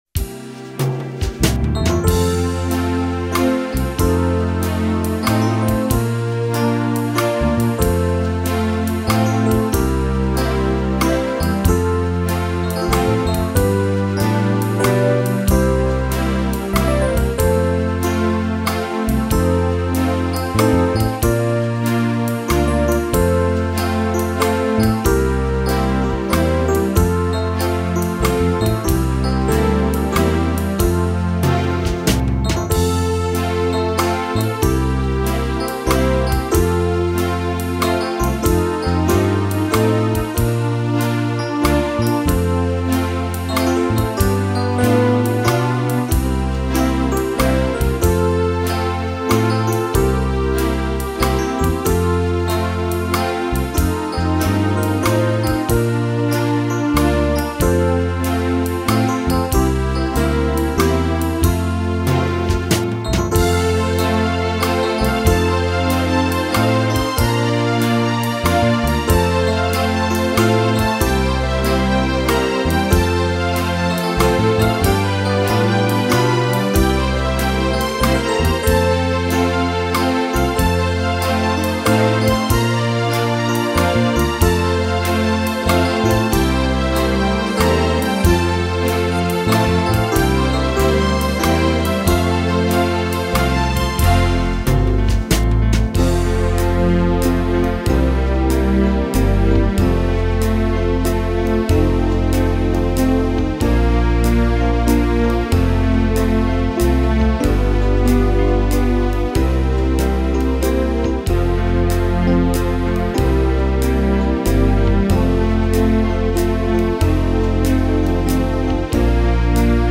Boston